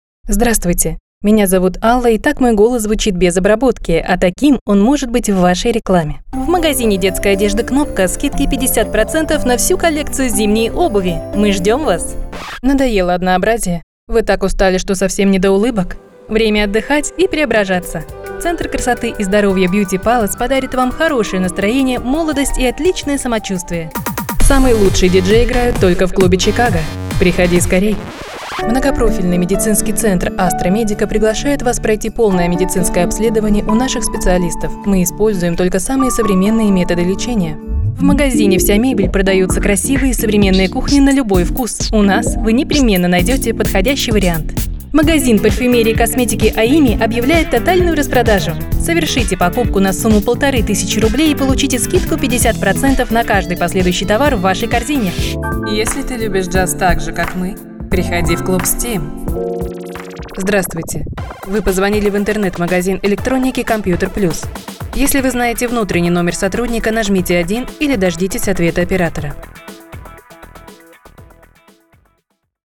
Сейчас диктор:
Любая подача текста на ваш выбор: мягкая и ласковая, энергичная, веселая или спокойная и загадочная.
Домашняя профессиональная студия.